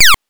laser_shot_10.wav